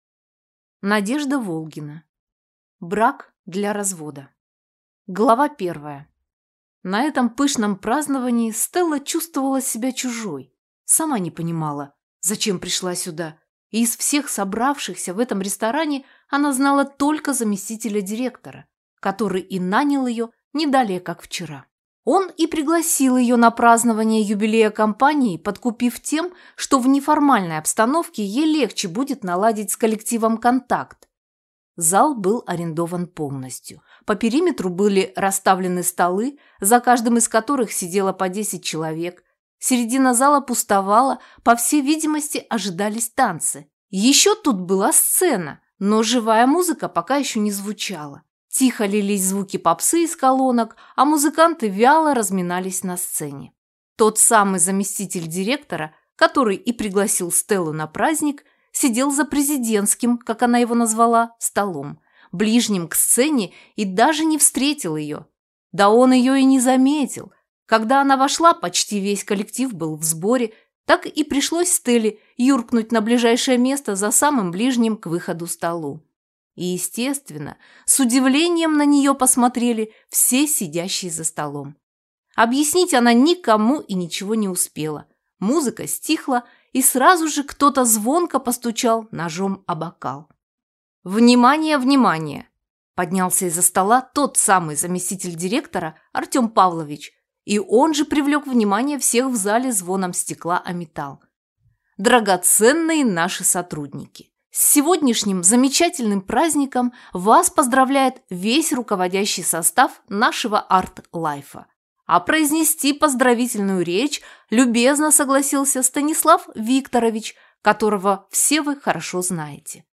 Аудиокнига Брак для развода | Библиотека аудиокниг